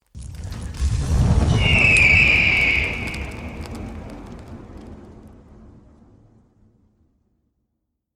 Phoenix Rebirth Single
Animals
Phoenix Rebirth Single is a free animals sound effect available for download in MP3 format.